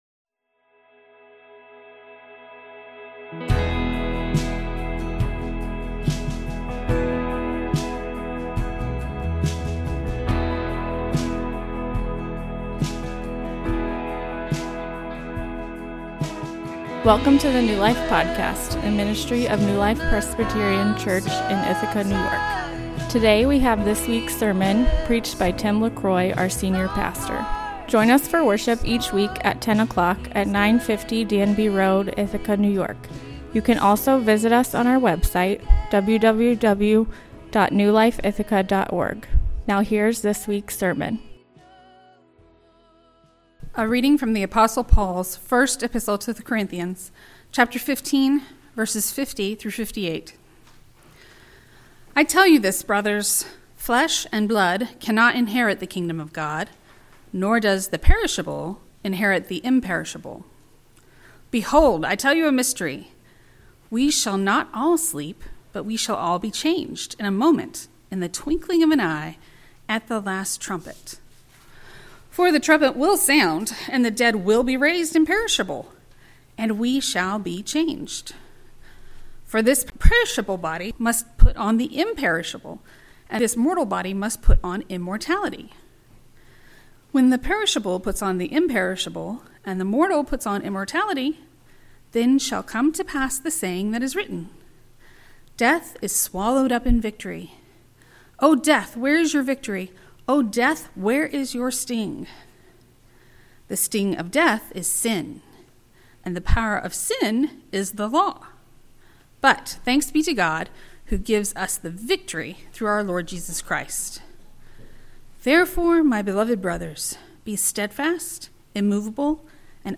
A sermon on the resurrection of the dead.